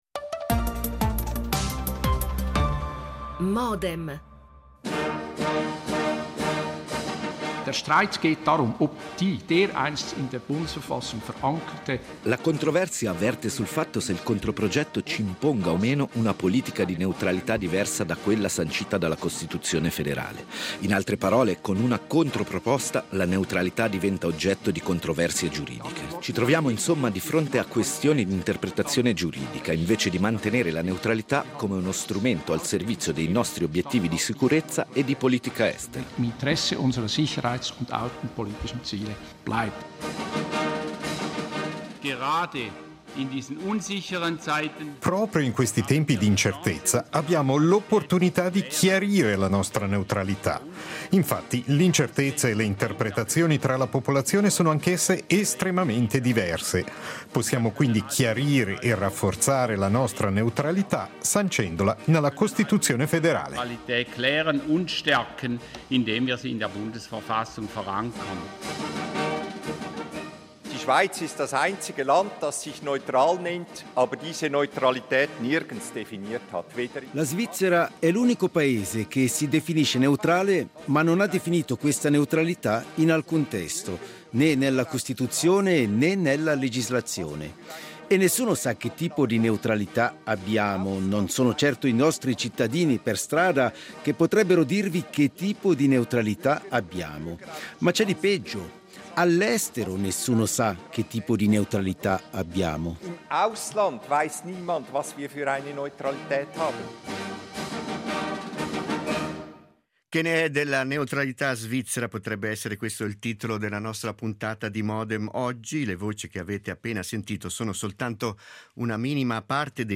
E con un’intervista registrata a Benedikt Würth , consigliere agli Stati Centro/SG
L'attualità approfondita, in diretta, tutte le mattine, da lunedì a venerdì